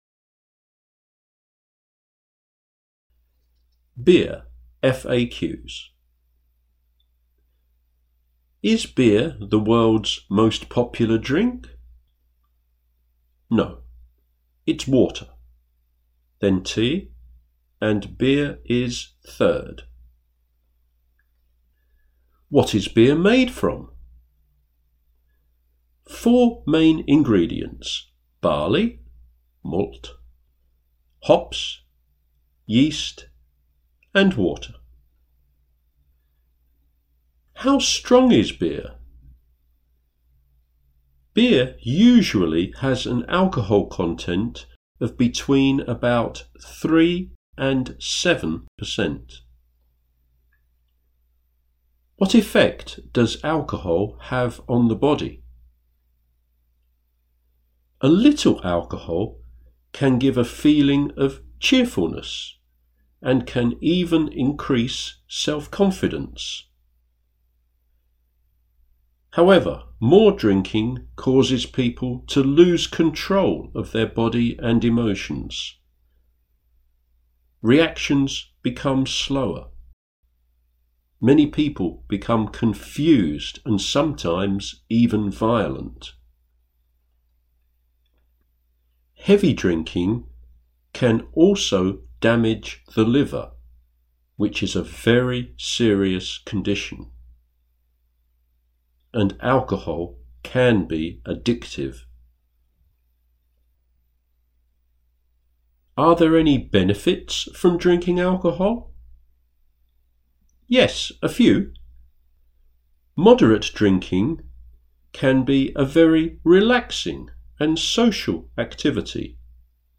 NARRATION
Beer FAQs audio narration